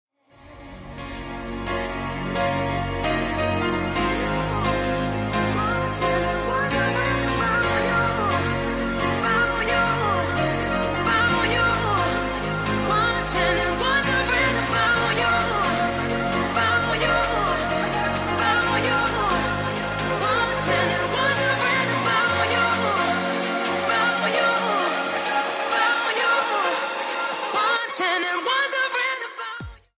Dance / Electronica